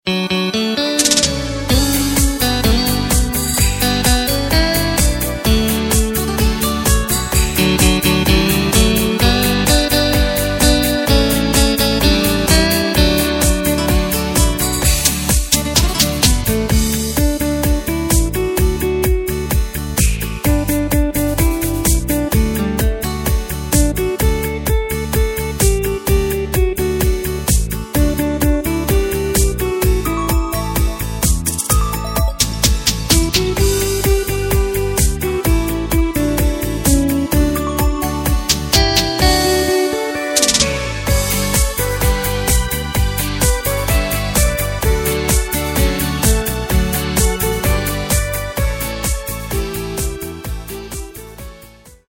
Takt:          4/4
Tempo:         128.00
Tonart:            D
Schlager aus dem Jahr 2013!